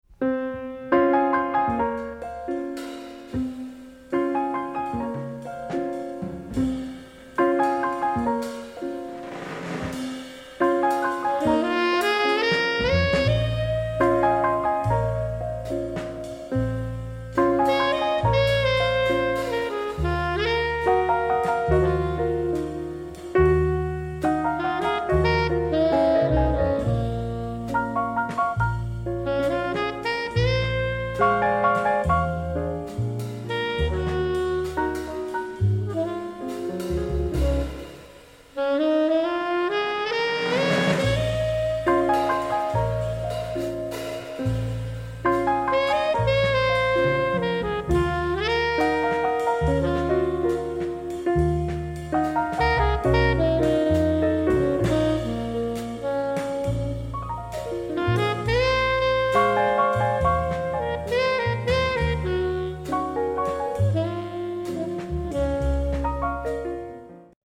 composizione jazz